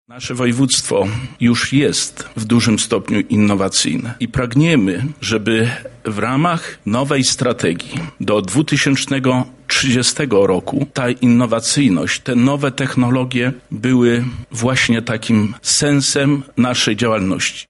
-mówi Zbigniew Wojciechowski, wicemarszałek województwa lubelskiego.